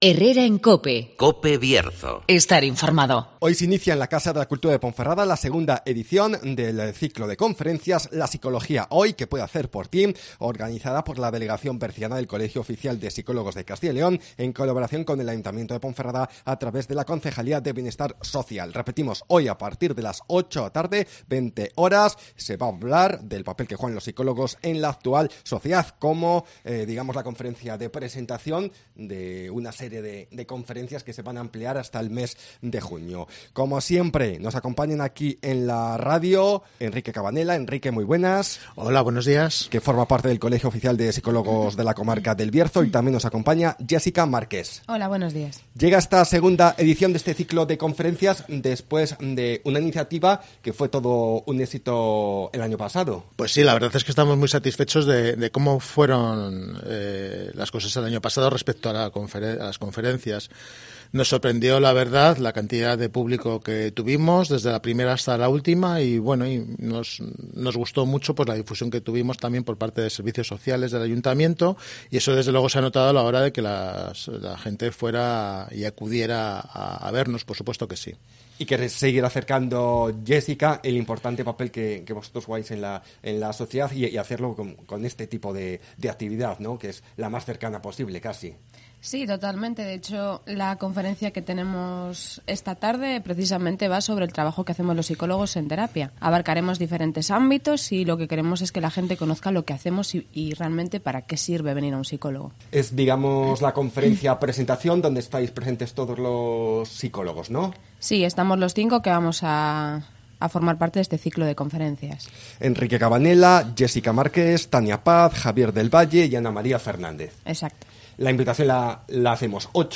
Escucha aquí todos los detalles con los psicólogos